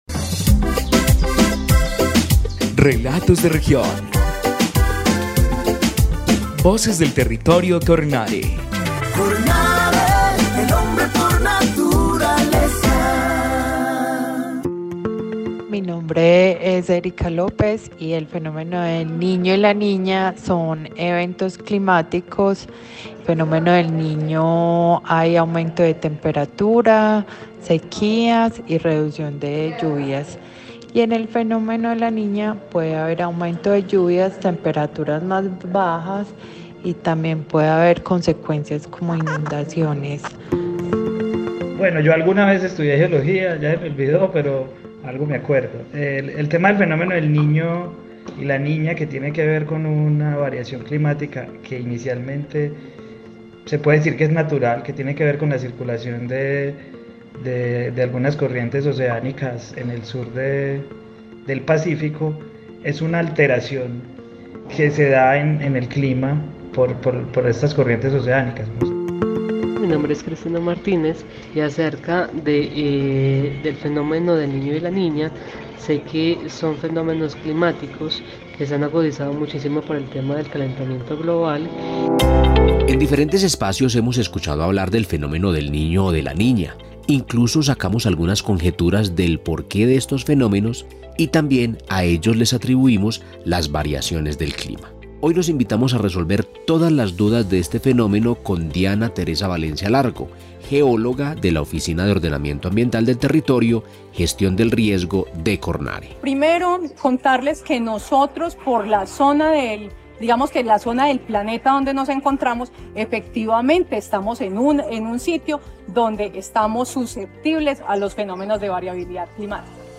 Programa de radio